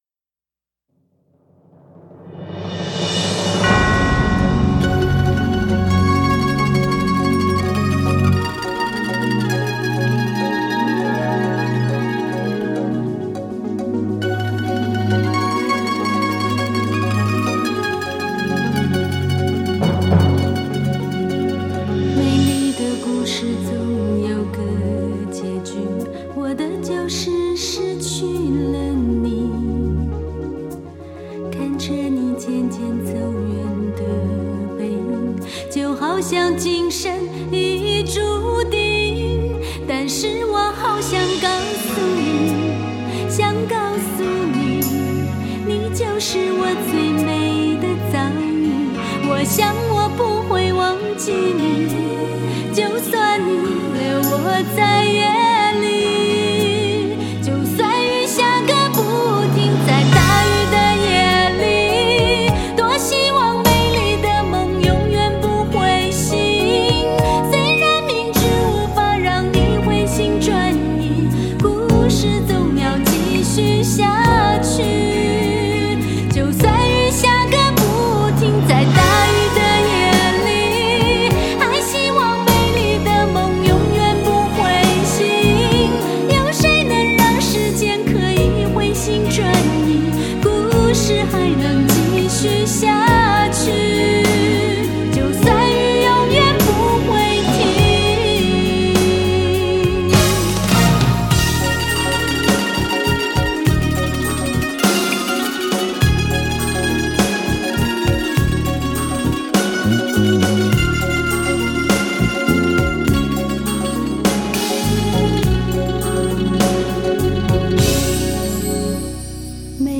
母带经重新数字处理